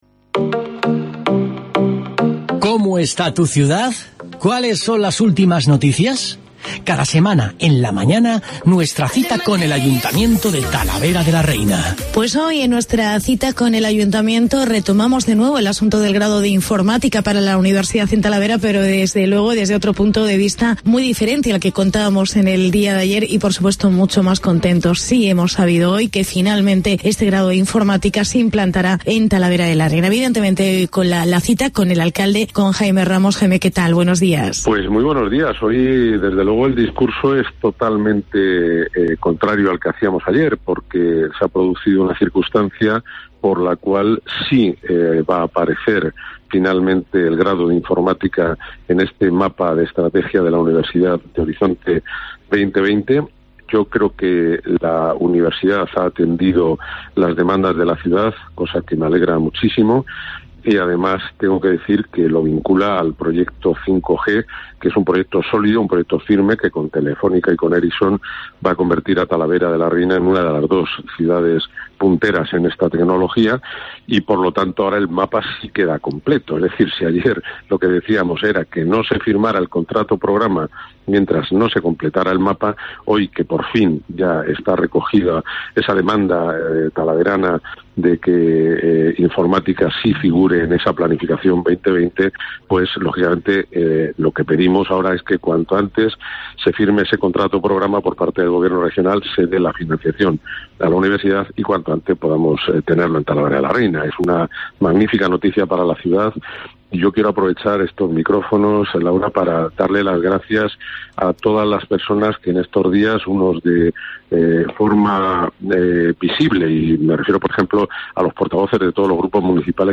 Entrevista al alcalde: Jaime Ramos